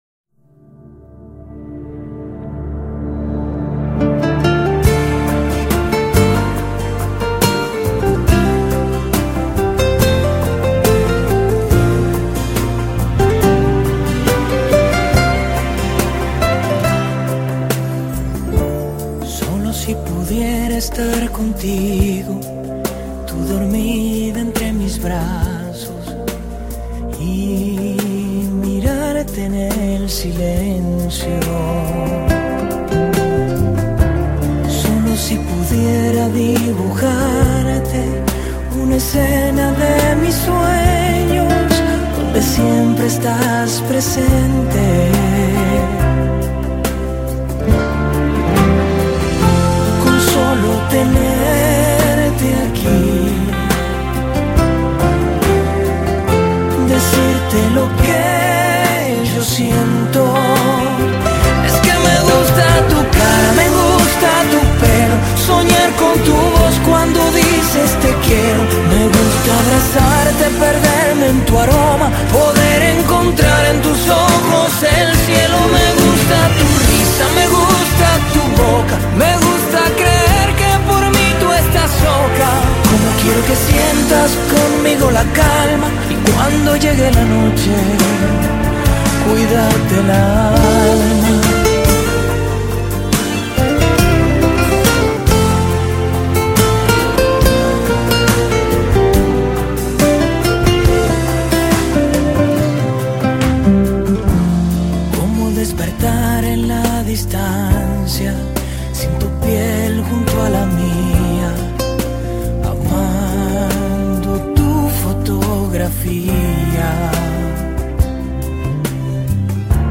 下面是我精选的十首经典西班牙语歌曲，曲风多为慢板抒情，希望大家喜欢。